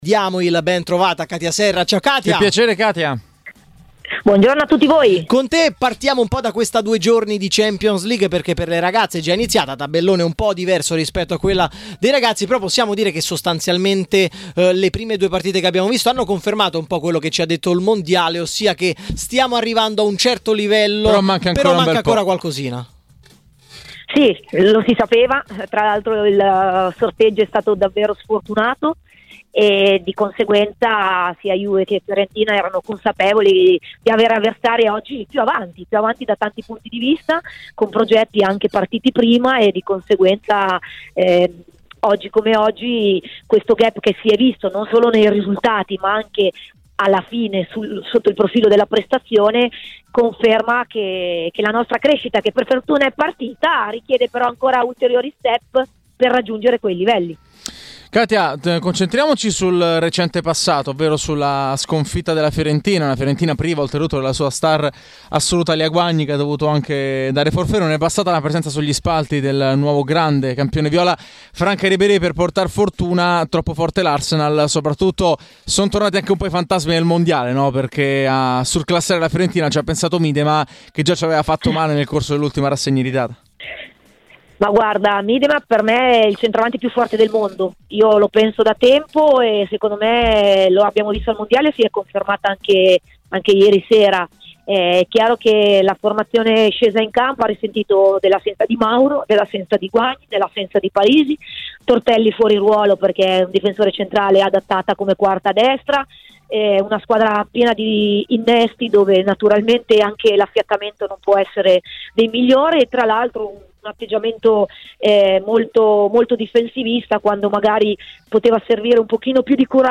Katia Serra, opinionista di Sky Sport, è intervenuta nel corso della trasmissione "Linea Diretta" di Tmw Radio per commentare le partite in Champions League di Fiorentina e Juventus e per presentare la nuova stagione di Serie A femminile.